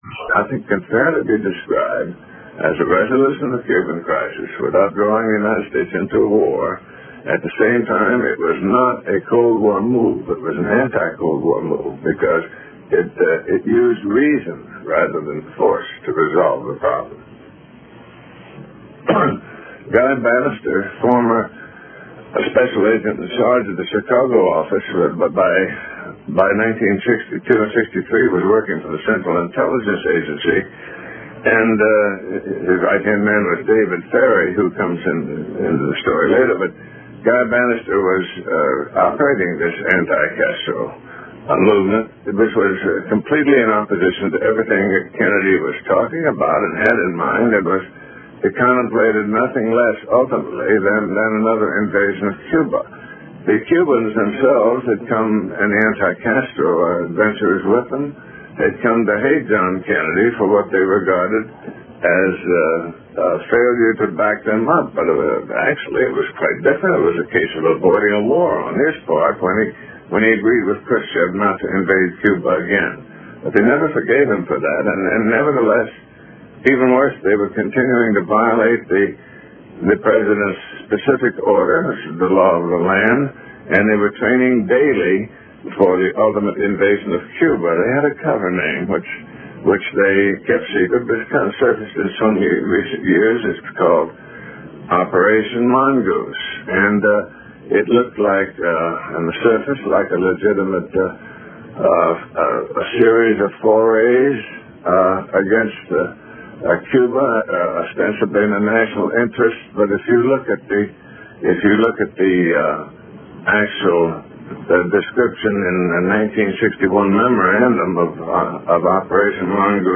Jim Garisson - JFK 1988 Broadcast 4